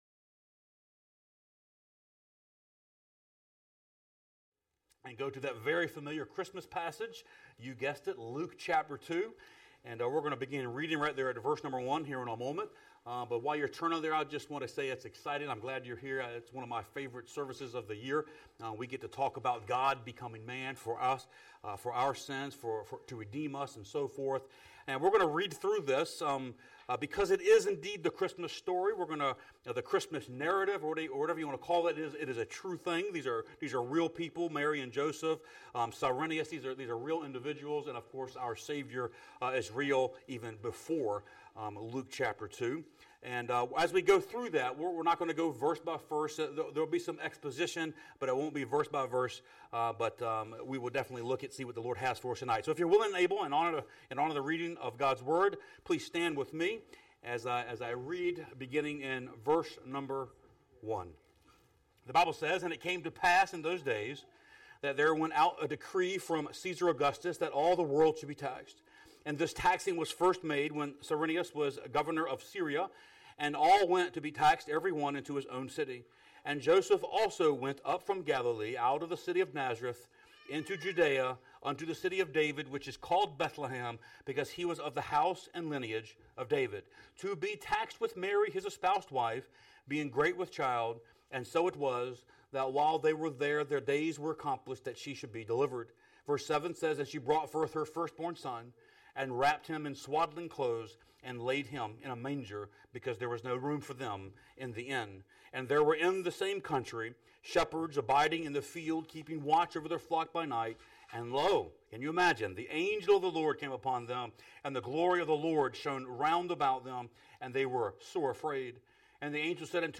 Sermons | Hohenfels Baptist Church